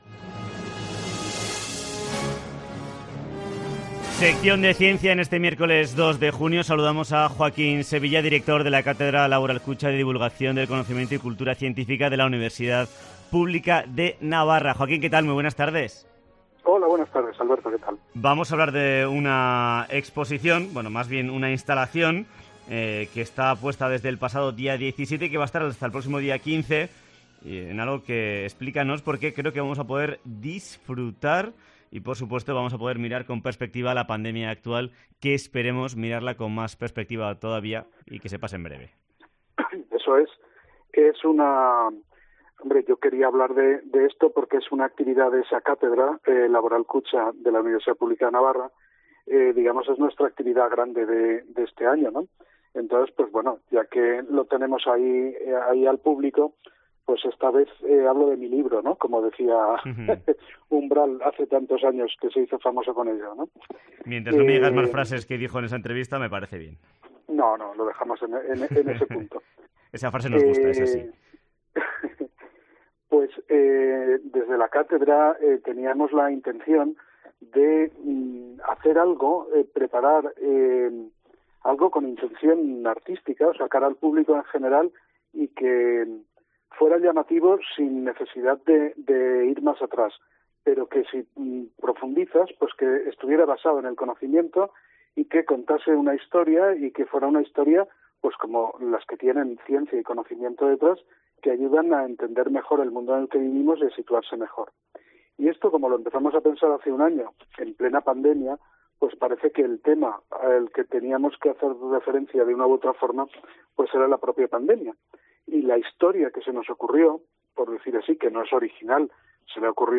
Sección de ciencia en COPE Navarra
Entrevista